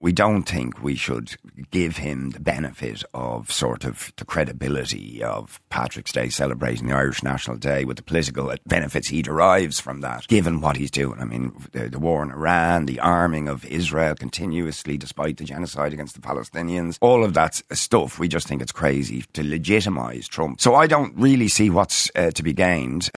However, People Before Profit TD Richard Boyd Barrett says it’s ridiculous the meeting is going ahead: